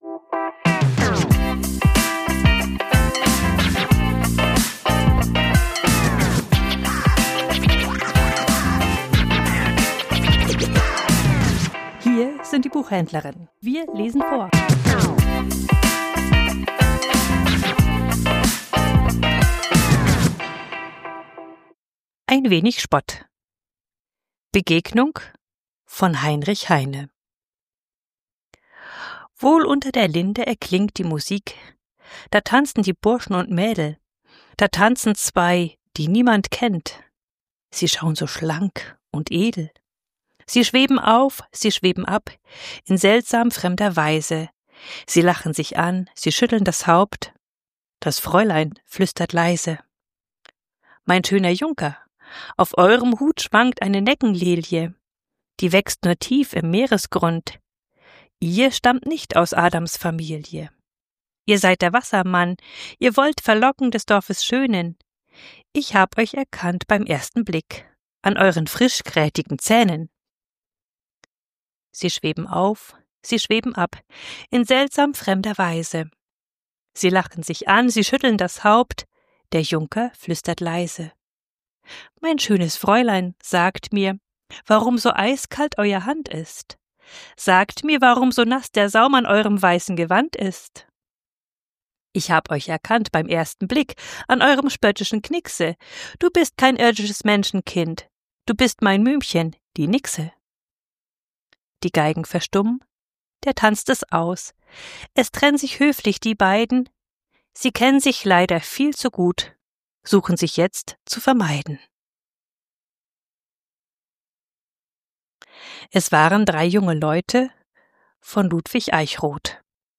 Vorgelesen: Ein wenig Spott